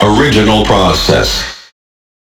完美适用于打造震撼的低音、丰富的节奏纹理和地下音乐氛围。